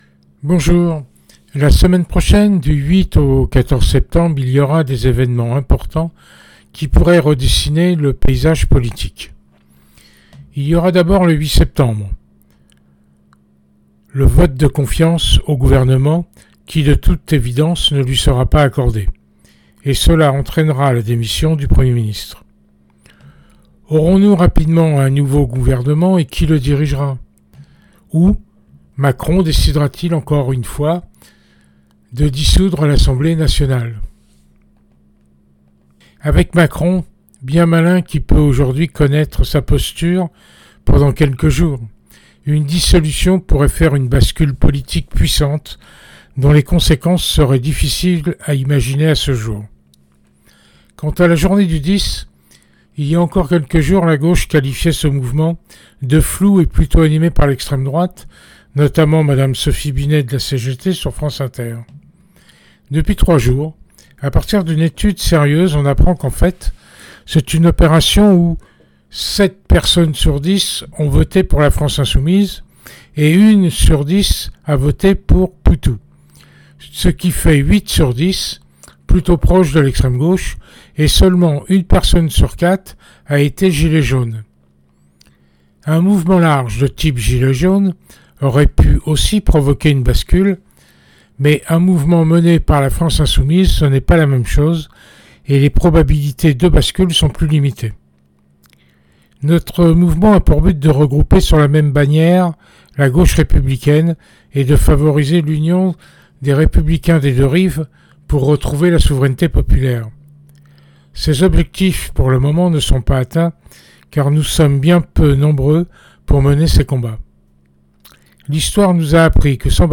Version audio de l’article